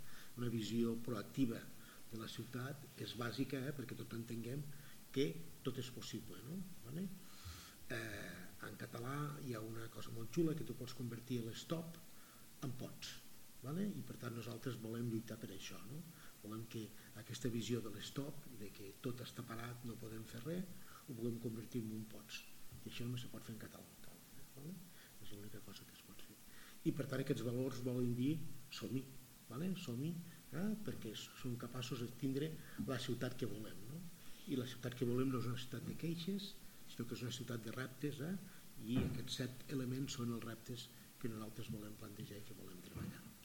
Talls de veu
Discurs del President de la Generalitat, Salvador Illa (i 2)